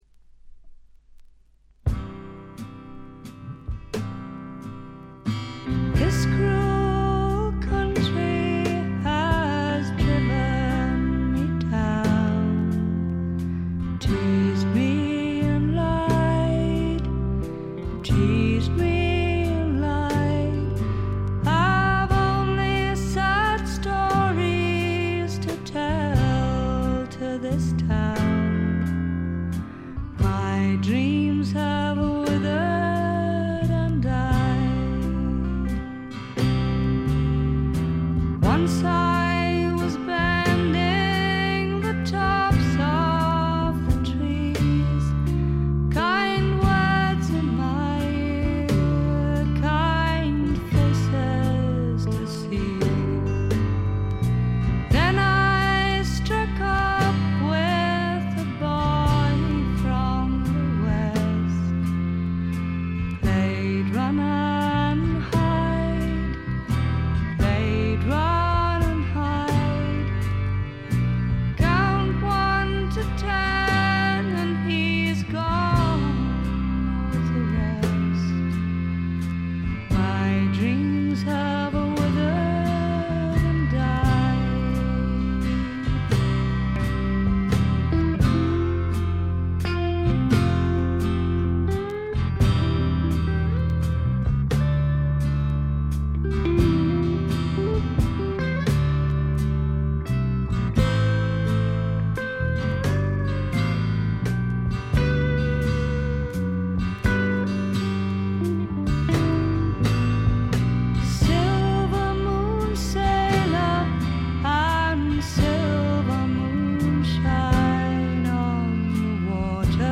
部分試聴ですが、ほとんどノイズ感無し。
英国のフォークロックはこう来なくっちゃというお手本のようなもの。
試聴曲は現品からの取り込み音源です。